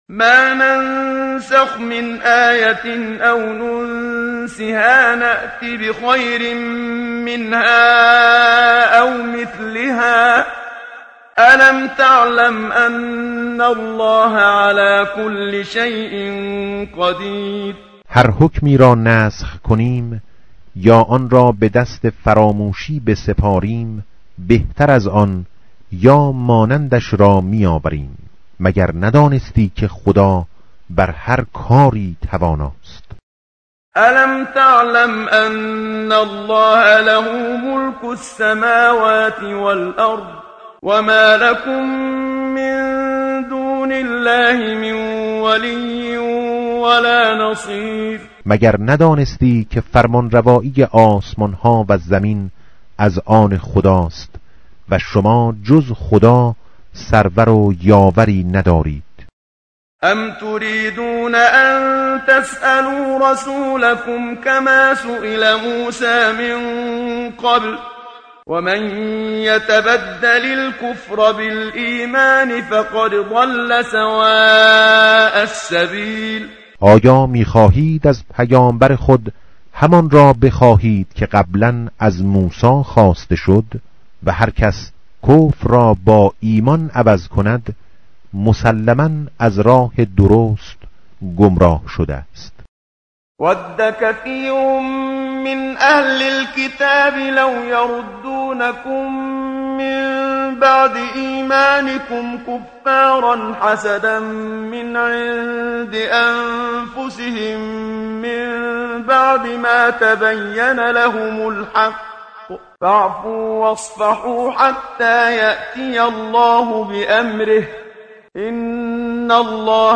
tartil_menshavi va tarjome_Page_017.mp3